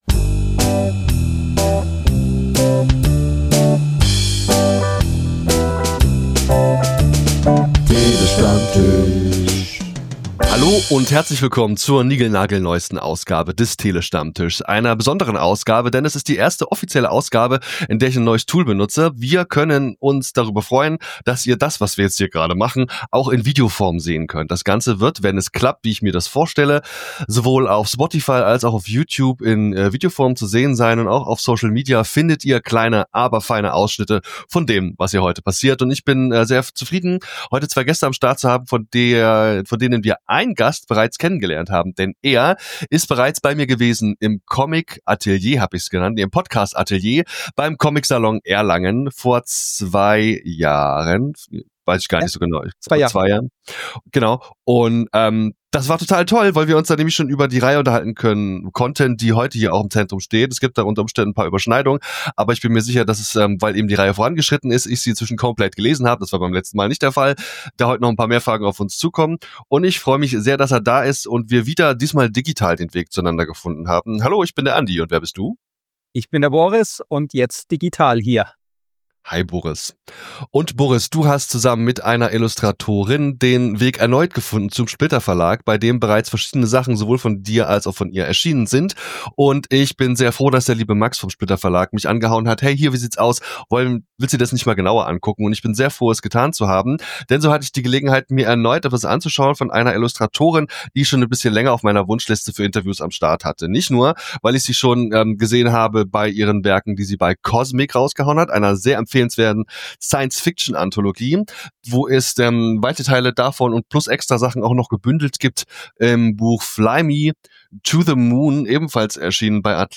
Short Crowd Cheer 2.flac